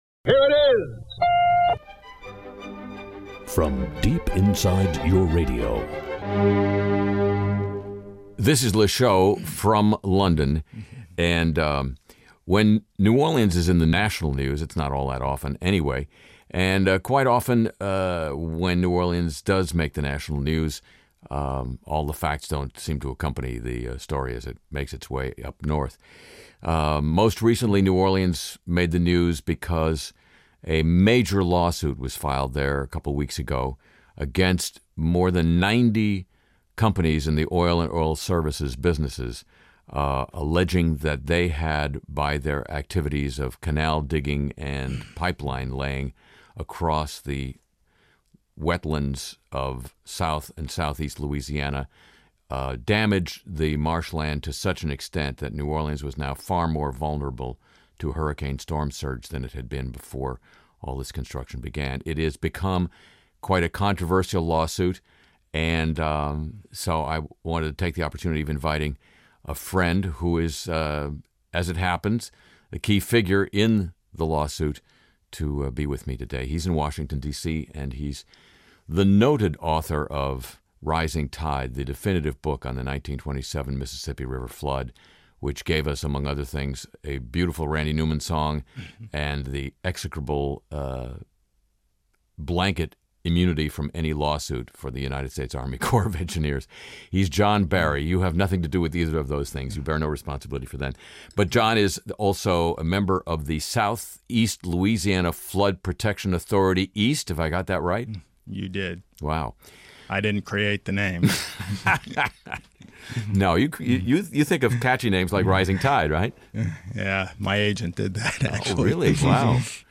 Conversation with John Barry: Author of Rising Tide and member of Southeast Louisiana Flood Protection Authority East, regarding their lawsuit against 90 oil and oil-services companies for the damage they've done to Lousiana's coastal wetlands